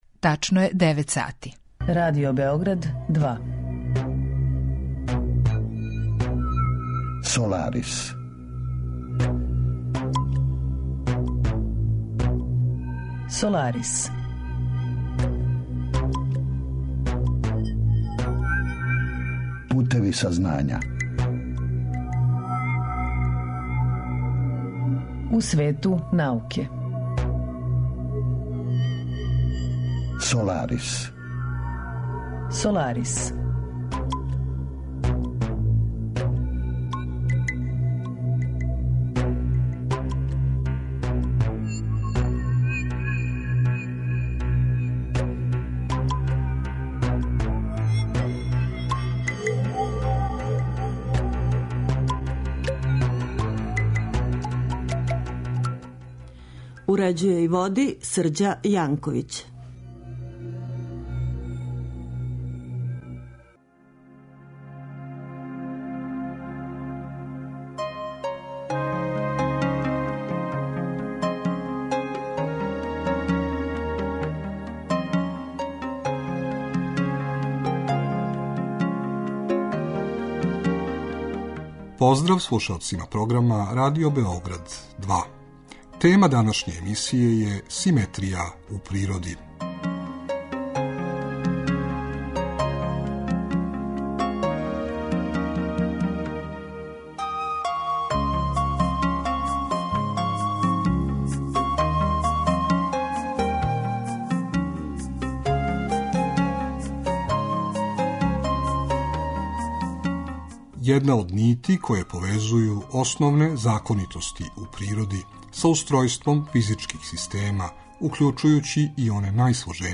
Кратка прича из историје науке посвећена је открићу Сатурновог месеца Титана које је 25. марта 1655. године извршио Кристијан Хајгенс. Емисија садржи и избор научних вести објављених у светским медијима од претходне среде, као и редовну рубрику "Ви сте то тражили", посвећену одговорима на питања слушалаца.